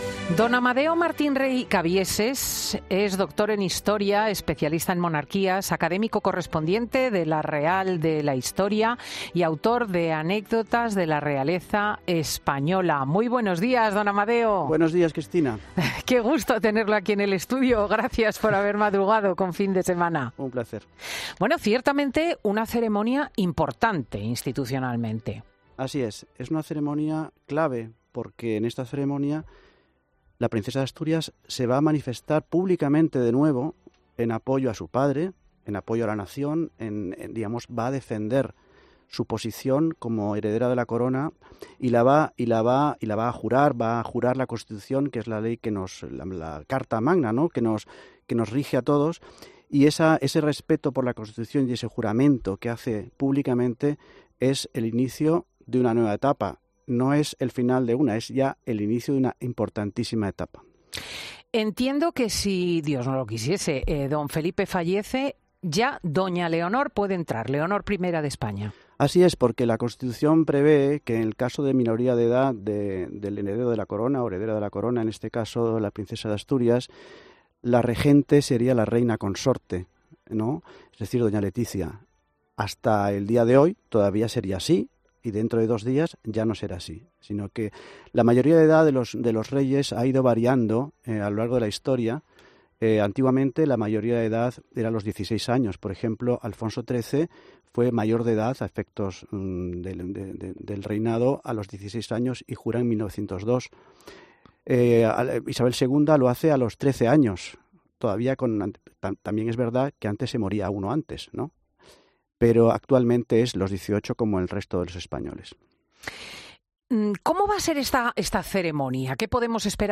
Repasamos cómo será ese acto con un doctor en Historia y experto en monarquías que nos da todas las claves en 'Fin de Semana'